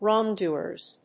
/ˈrɔˈŋduɝz(米国英語), ˈrɔ:ˈŋdu:ɜ:z(英国英語)/